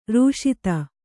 ♪ rūṣita